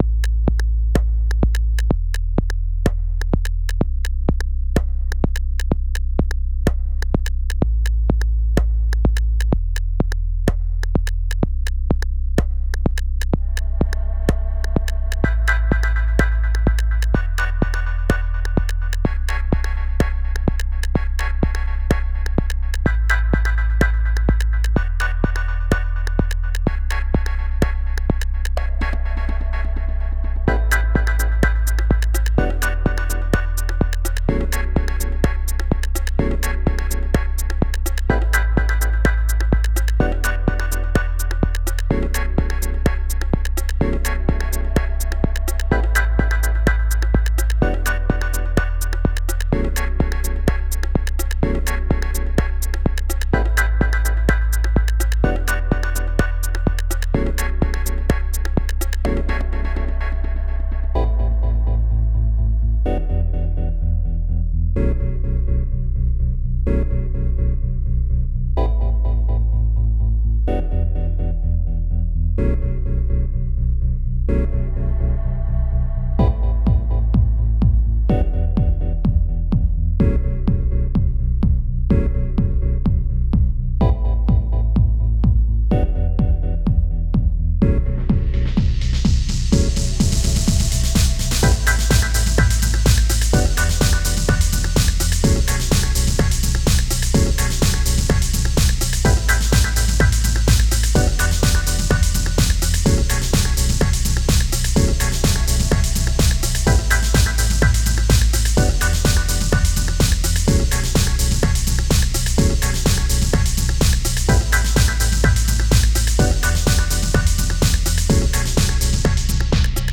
Dub Tech House